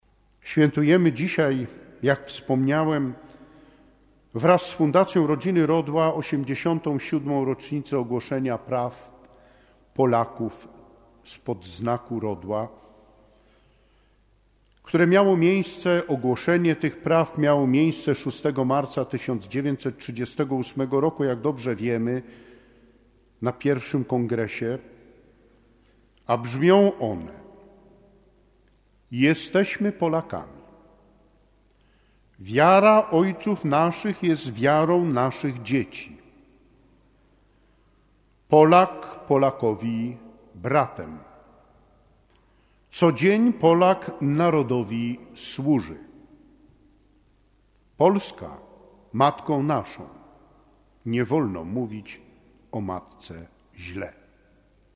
wspomniał też o uroczystości, która zgromadziła wiernych w Katedrze Wrocławskiej w niedzielę 9 marca 2025 r.